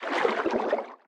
Sfx_creature_brinewing_swim_slow_04.ogg